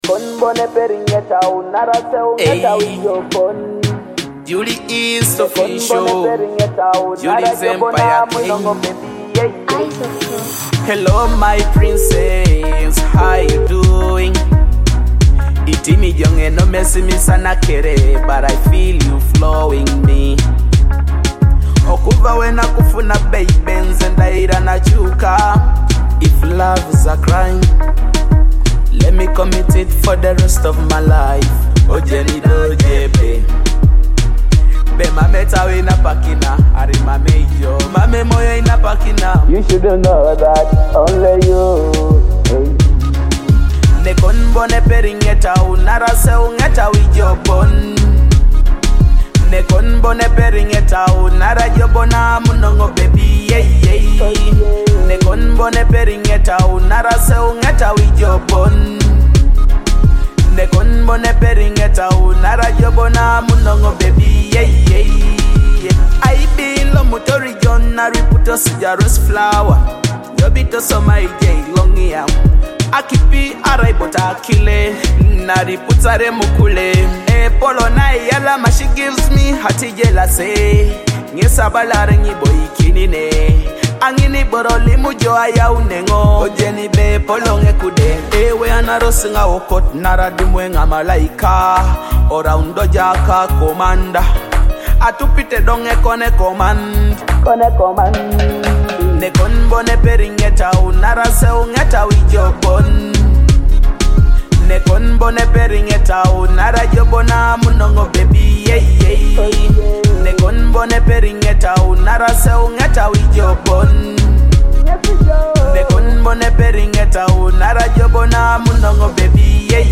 a fresh Teso love hit
enjoy authentic regional sound at its best.